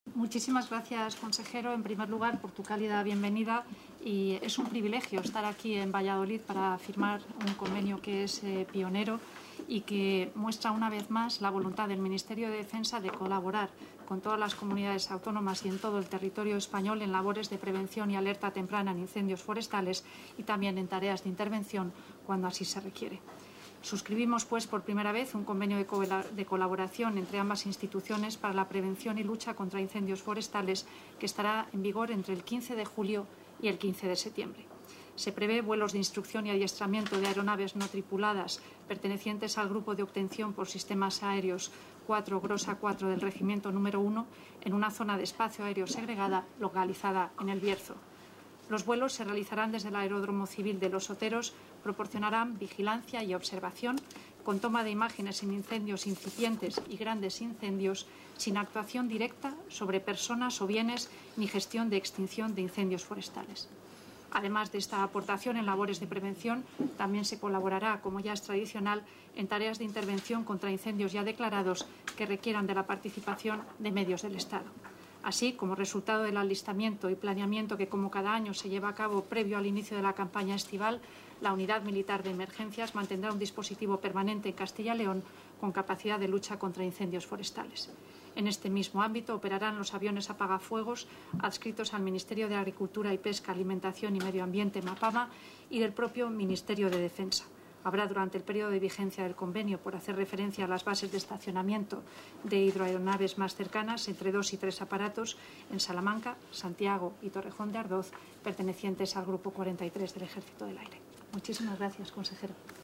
Audio consejero de Fomento.